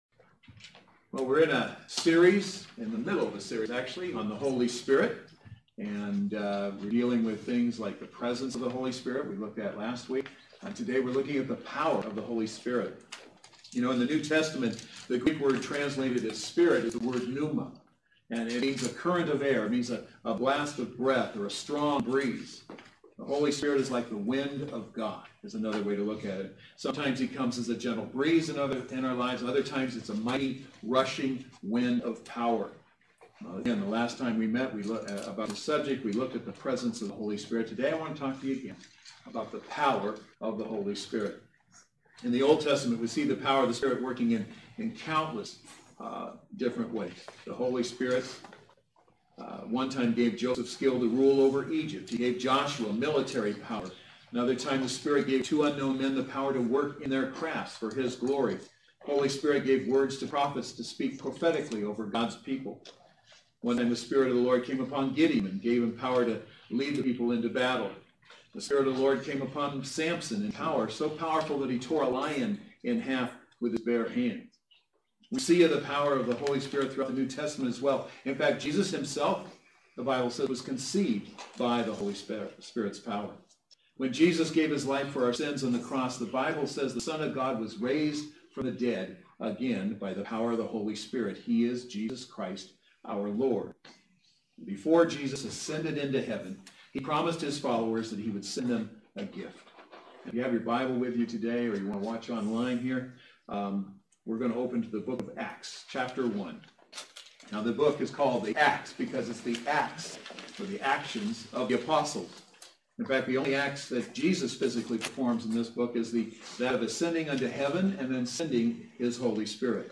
Service Type: Saturday Worship Service